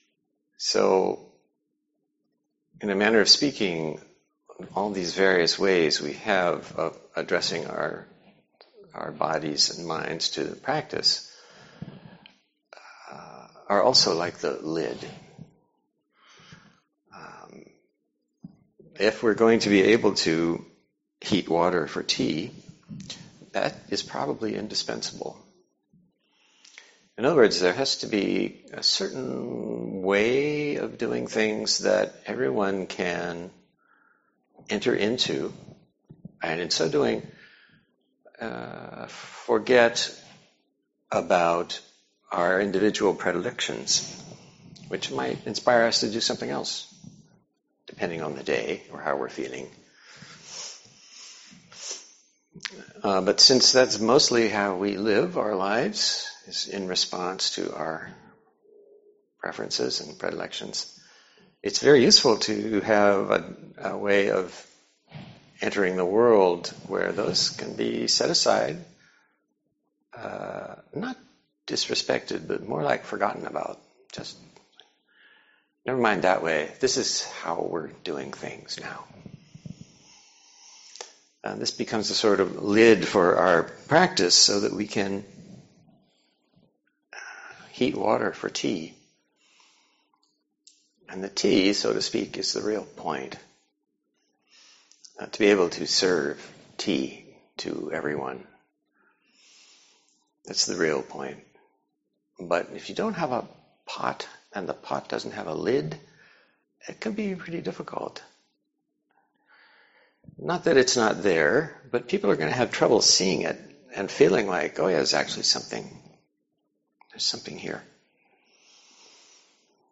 Dharma Talk